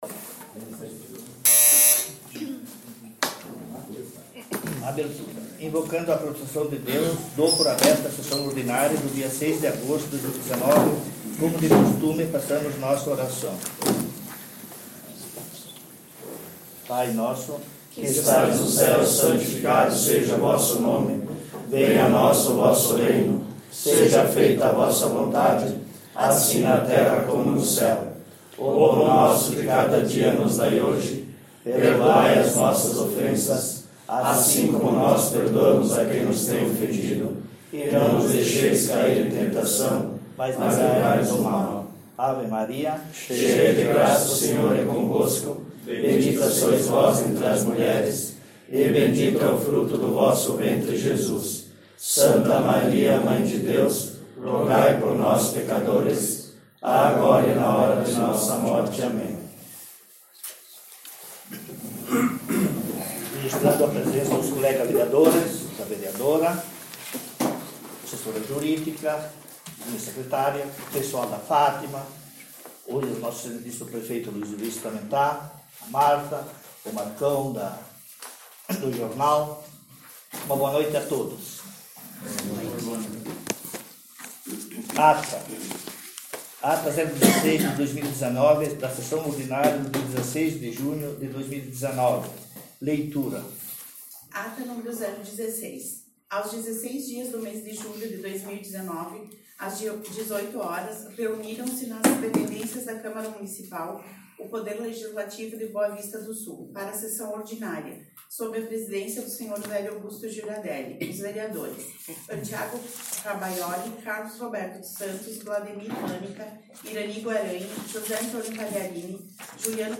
Sessão Ordinária dia 06/08/19